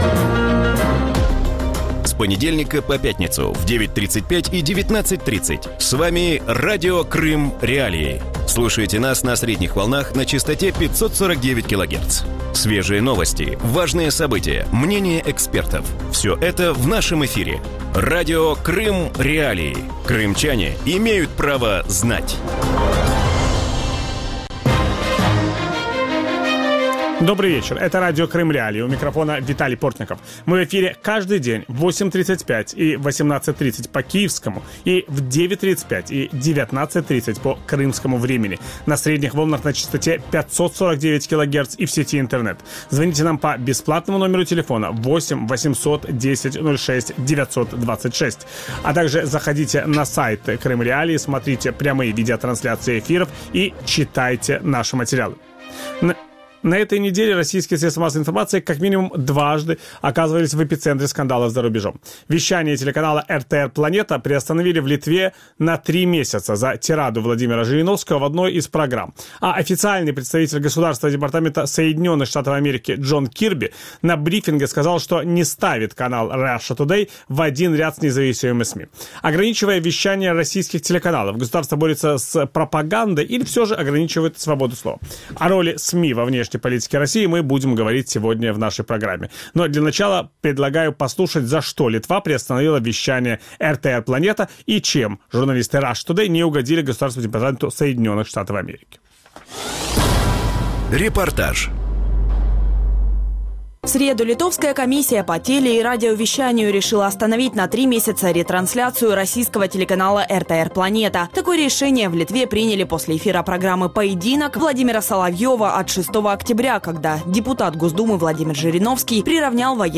У вечірньому ефірі Радіо Крим.Реалії говорять про роль засобів масової інформації у зовнішній політиці Росії. Чому доступ до російських ЗМІ обмежують в Україні та балтійських країнах і чи можна назвати боротьбу з пропагандою наступом на свободу слова? На ці питання відповість член Національної ради з електронним ЗМІ Латвії Айнарс Дімантс. Ведучий: Віталій Портников.